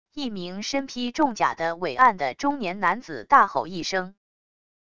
一名身披重甲的伟岸的中年男子大吼一声wav音频